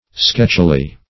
Sketchily \Sketch"i*ly\, adv.